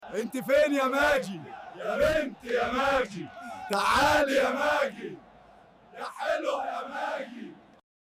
شباب الساحل كله في المظاهرة